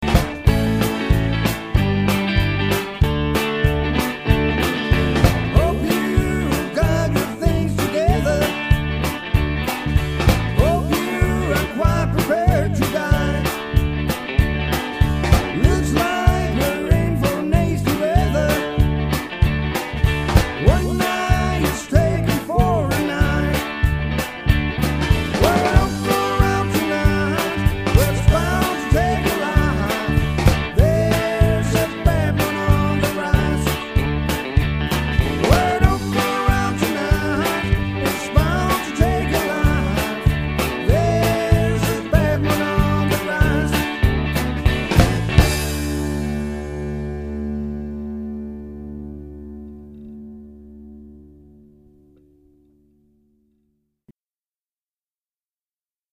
Classic-Rock